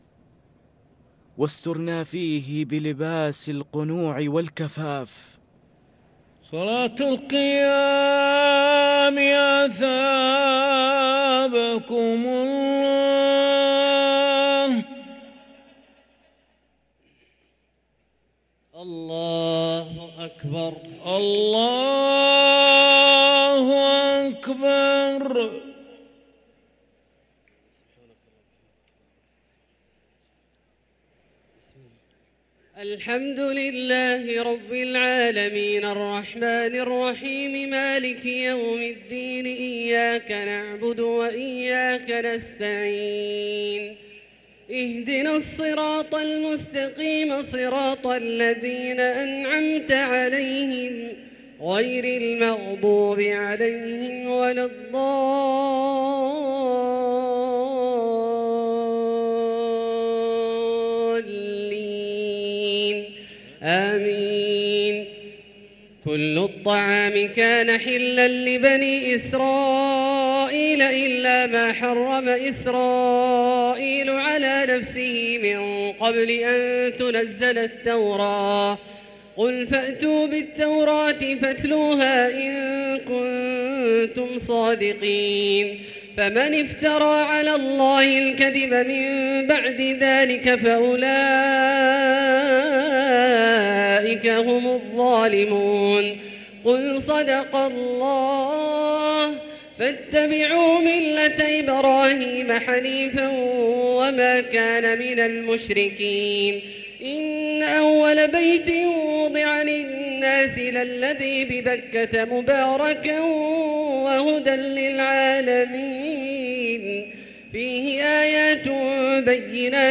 صلاة التراويح ليلة 5 رمضان 1444 للقارئ عبدالله الجهني - الثلاث التسليمات الأولى صلاة التراويح